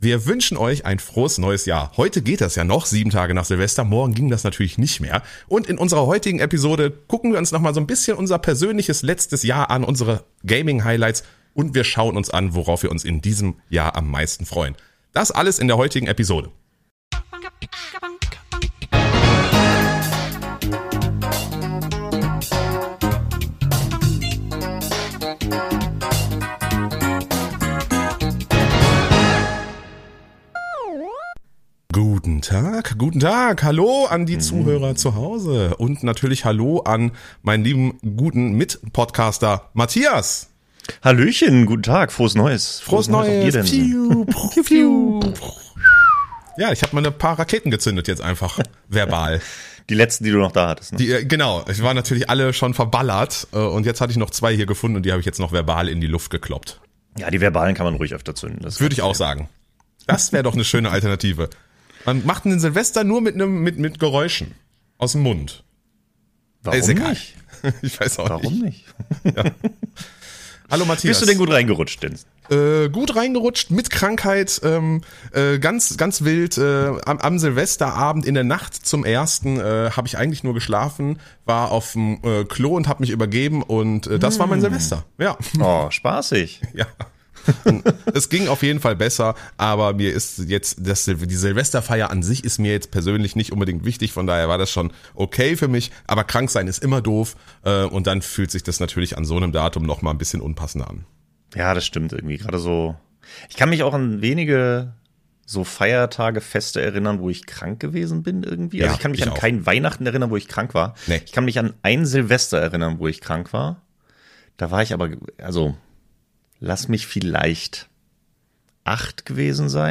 Introsprecher Hans-Georg Panczak